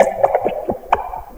PERC FXLP1-R.wav